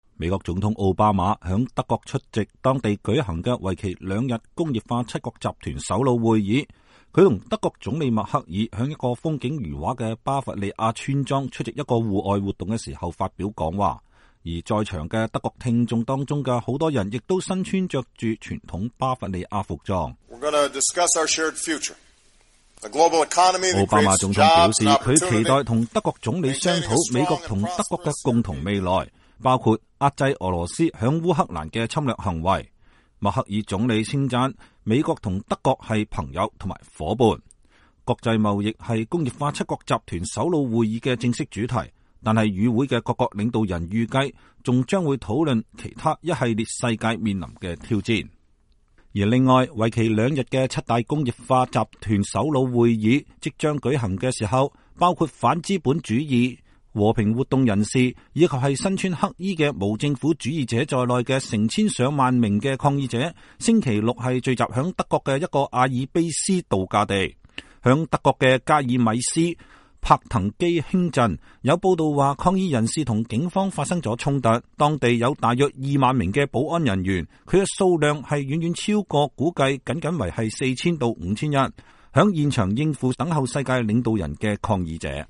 他和德國總理默克爾在一個風景如畫的巴伐利亞村莊出席一個戶外活動時發表講話。